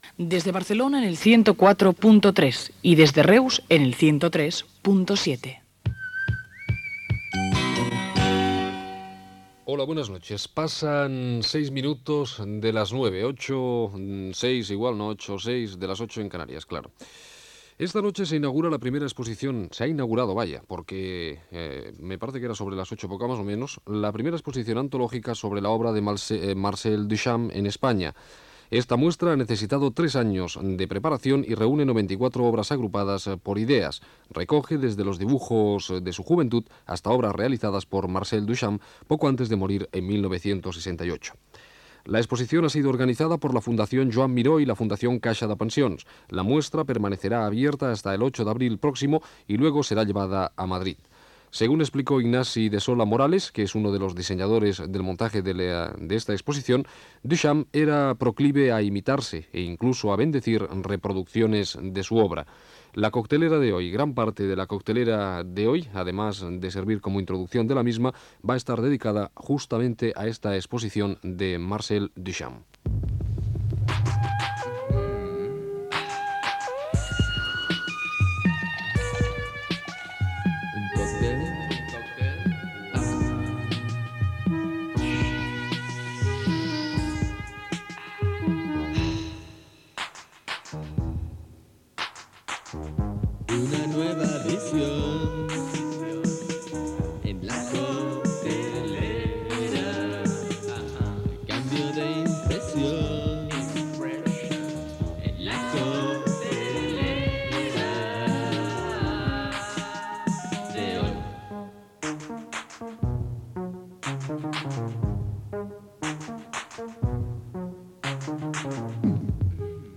Freqüències d'emissió a Reus i Barcelona, hora, inauguració d'una exposició de Marcel Duchamp a Barcelona, careta del programa, telèfons de l'emissora, tema musical
FM